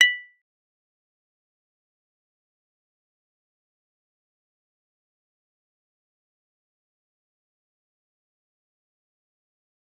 G_Kalimba-B6-f.wav